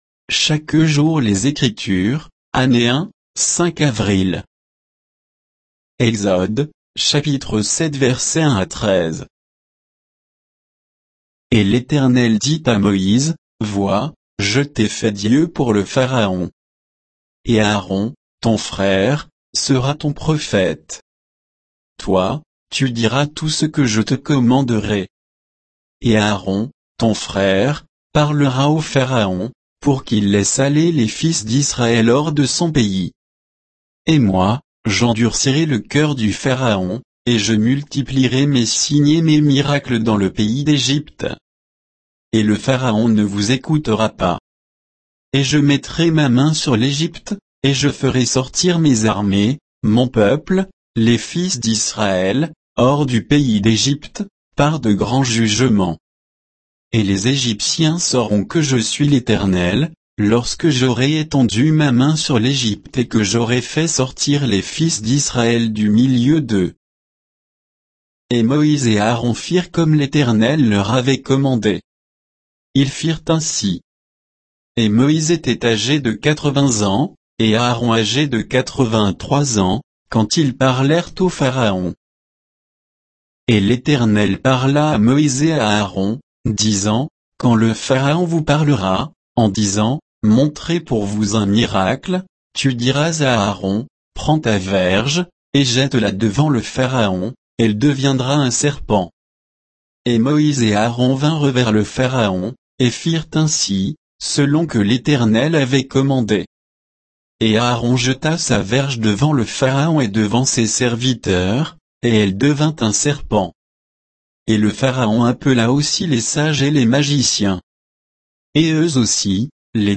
Méditation quoditienne de Chaque jour les Écritures sur Exode 7